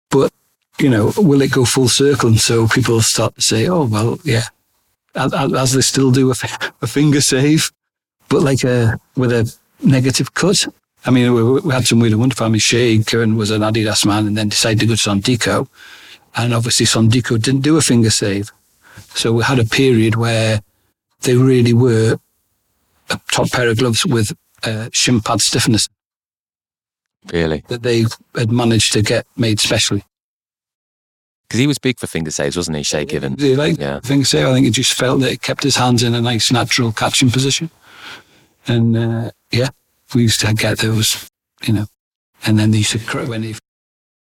I couldn’t find any distortion in the signal only problems with loudness dropping below ideal levels for a podcast.
I put your audio through some compressors to even out the dynamic range changes and got this:-
There are a few places where the audio quality changes and affects the overall sound quality.